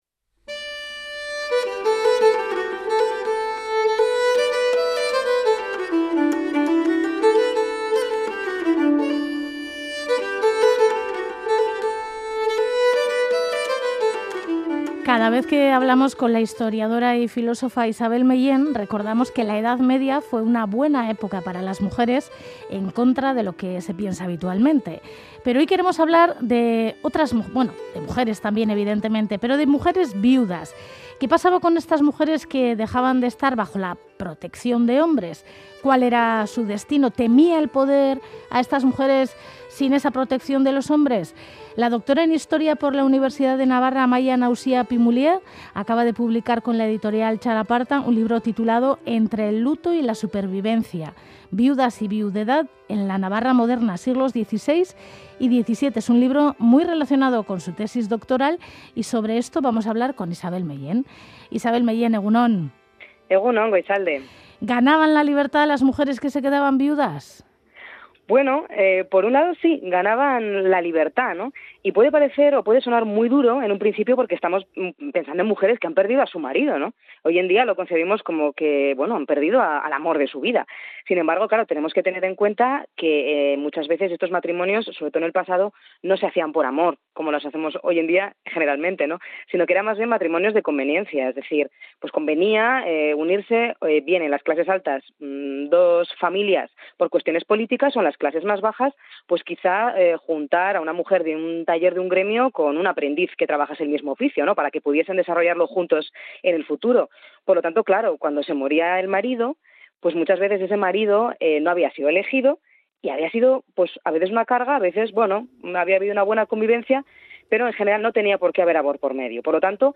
Conversaciones